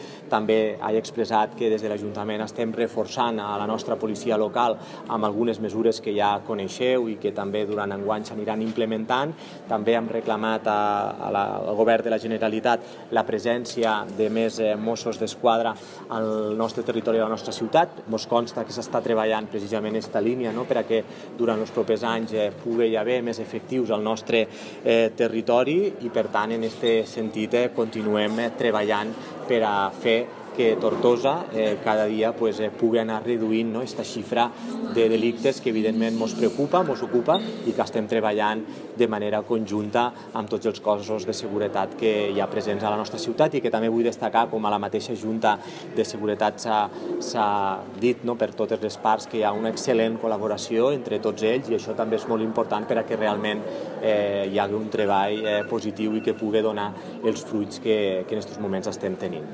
L’alcalde ha explicat també el compromís del govern de seguir reforçant la plantilla de la Policia Local de Tortosa, per millorar la seguretat i la vigilància a la nostra ciutat, amb mesures com l’adquisició d’un dron i un parell de vehicles policials, l’adquisició d’un mesurador mòbil per vehicles de mobilitat personal (VMP) i la creació d’una plaça de sotsinspector de la Policia Local.